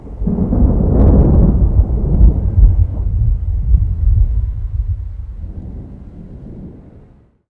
thunder_far_away_1.wav